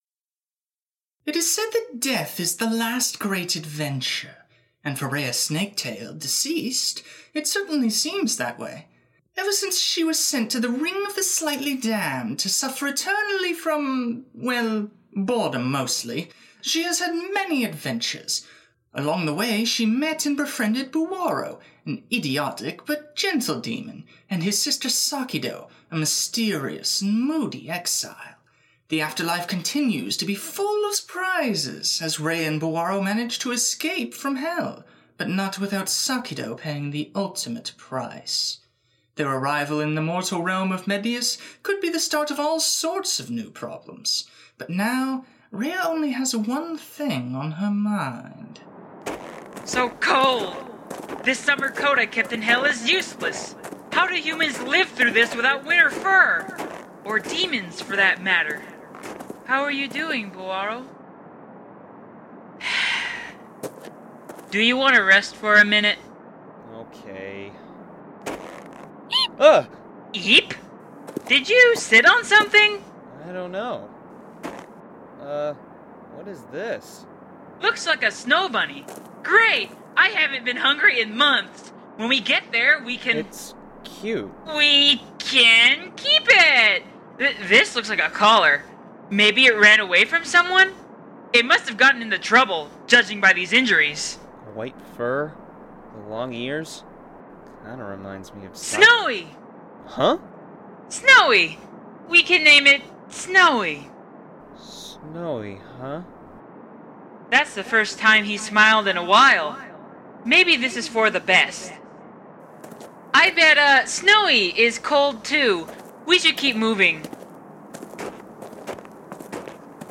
Radio Play
From 2010 to 2012, the SD community pulled itself together to create a radio play version of the comic’s story.